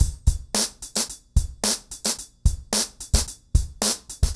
RemixedDrums_110BPM_04.wav